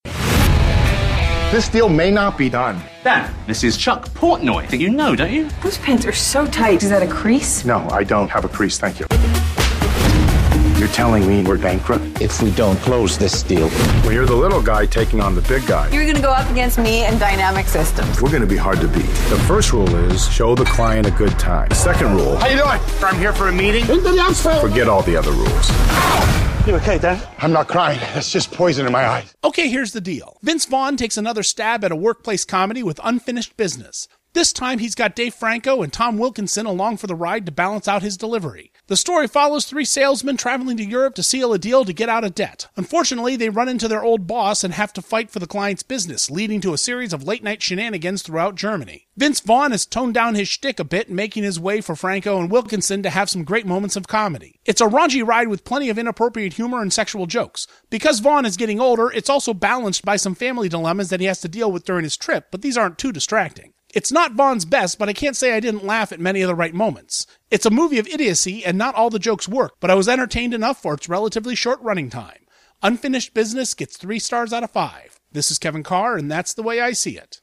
‘Unfinished Business’ Movie Review